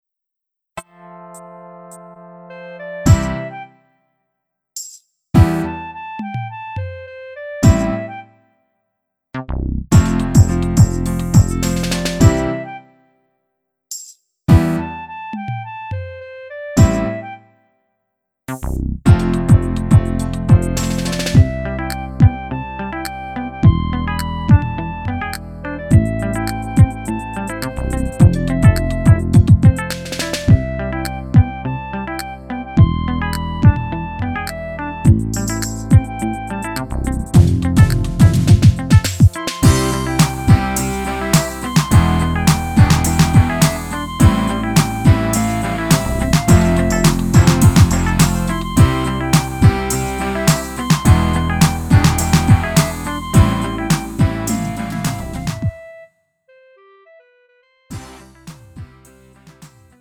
음정 원키 장르 가요